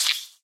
mob / silverfish / hit3.ogg
should be correct audio levels.
hit3.ogg